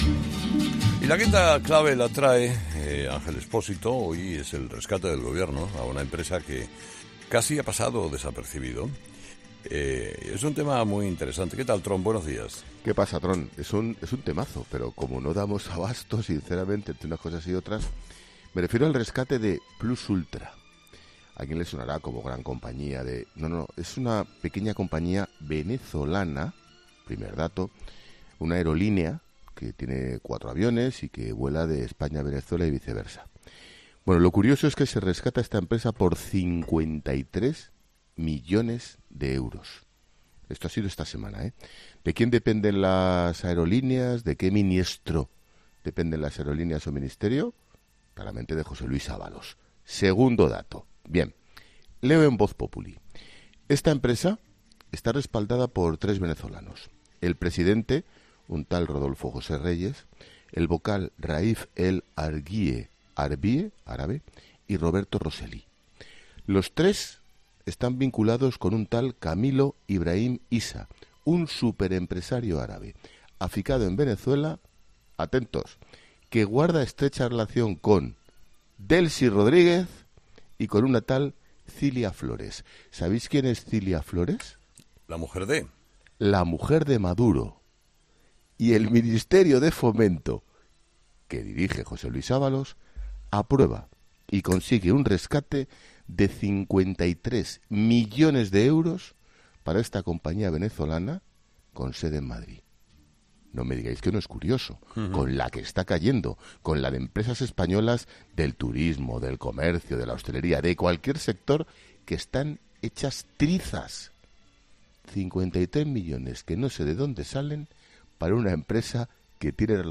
Ángel Expósito hace su paseíllo en 'Herrera en COPE'